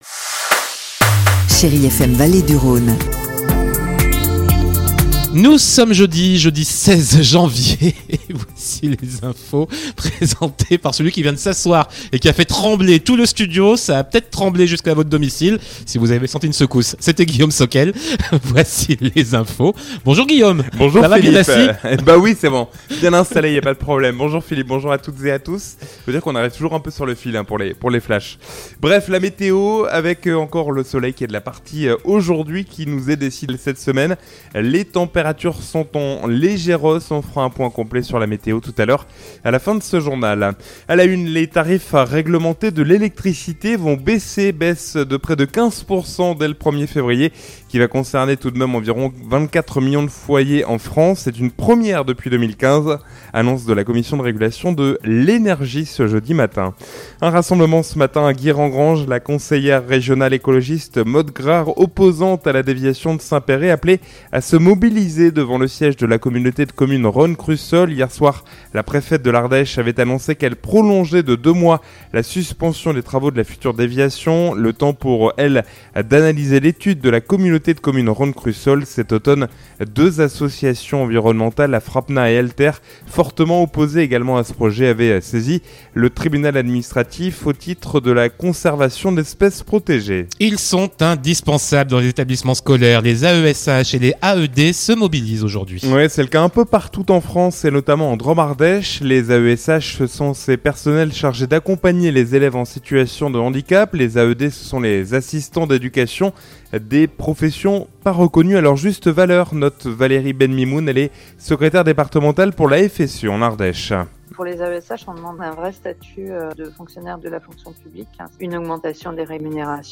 Jeudi 16 janvier : Le journal de 12h